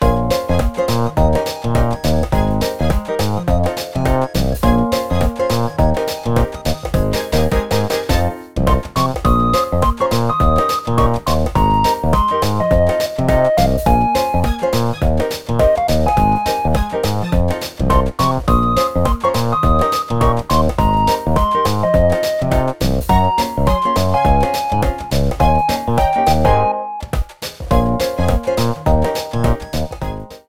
Updated to high quality & fade out
Fair use music sample